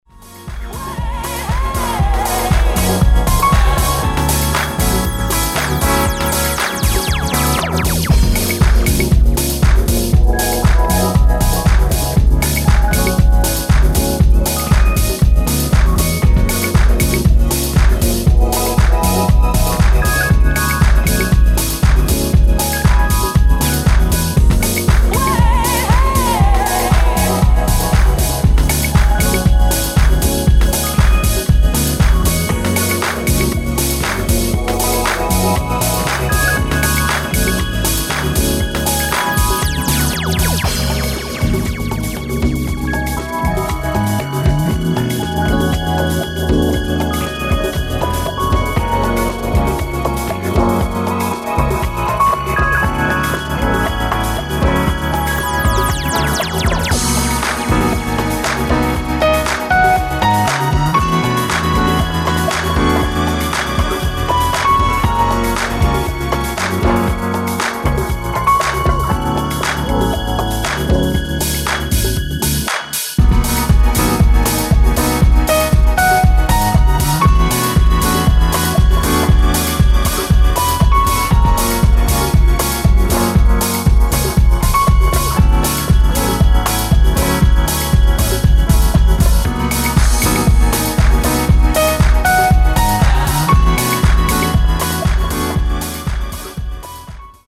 HOUSE | DEEP HOUSE